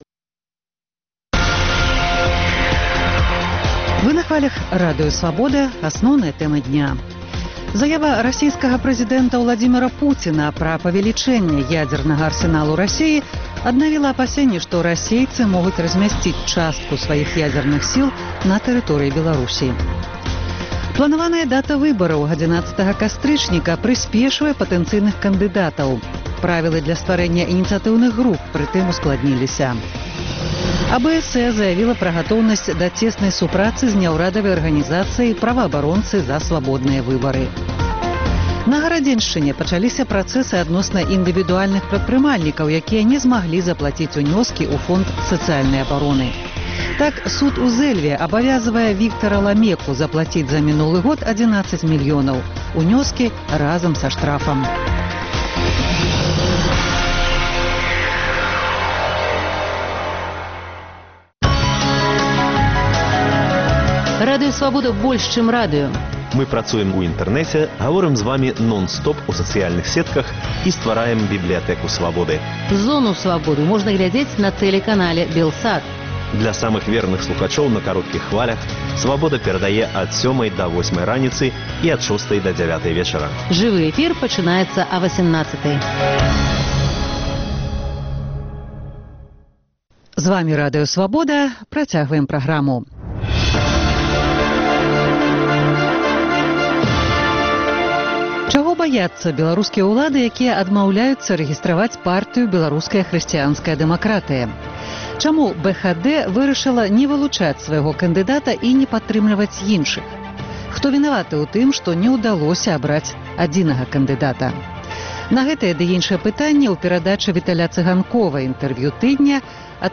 Інтэрвію тыдня: Павал Севярынец